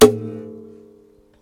ding hit metal ring ting tone sound effect free sound royalty free Sound Effects